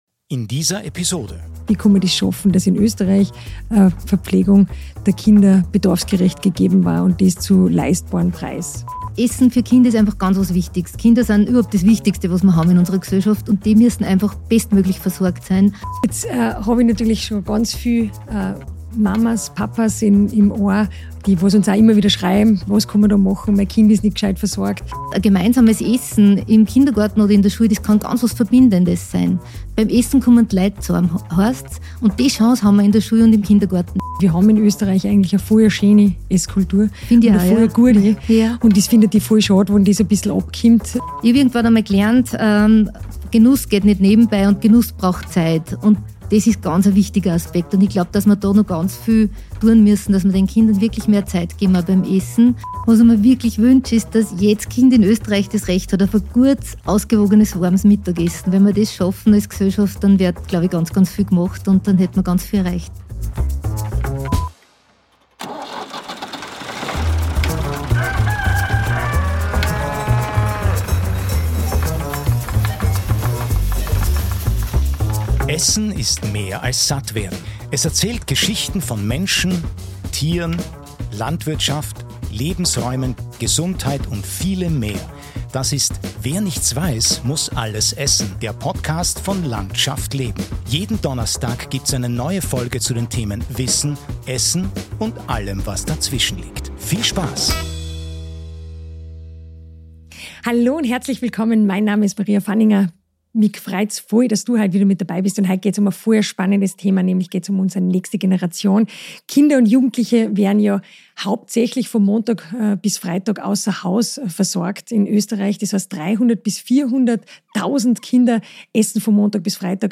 Sie diskutieren, wie ausgewogene Ernährung im Bildungsalltag gelingt, welchen Beitrag Ernährungswissenschaften leisten und welche Rolle kulturelle Einflüsse spielen. Ein Gespräch über Ernährung, Verantwortung und warum Gemeinschaftsverpflegung ein Schlüssel zu einem guten Lebensstil für Kinder ist.